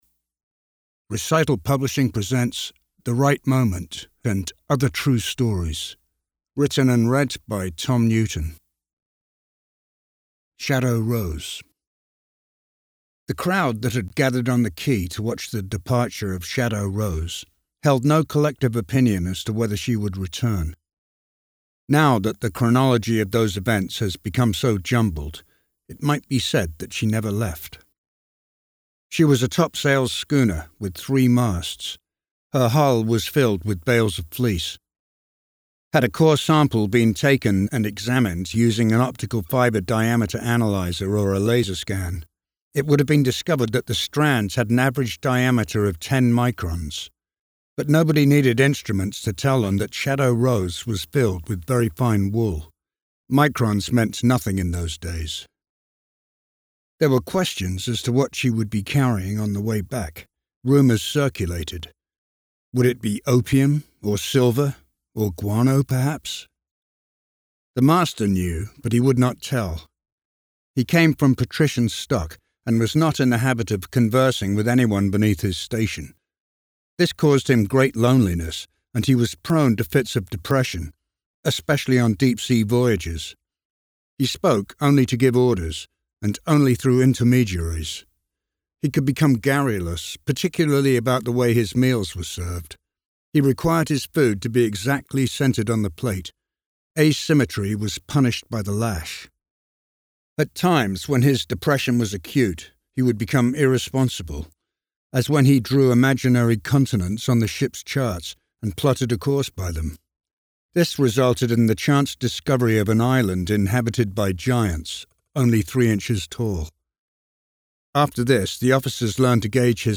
The Right Moment & Other True Stories is available as an audio book